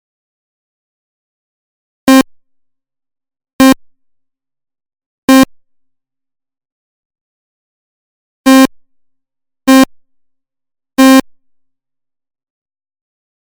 Первые 3 звука в примере, это нормальная атака, какой она должна быть в типичных случаях. Вторые три звука, так звучит типичный ПРО-800 когда у него Атака в нуле стоит. Атаки нет совсем, черепашья скорость АДСР, делает звук таким, какой слышно в последних 3-ех звуках. Вложения ADSR Fast and Slow.mp3 ADSR Fast and Slow.mp3 533,4 KB · Просмотры: 1.681